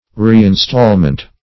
Reinstallment \Re`in*stall"ment\ (-ment), n.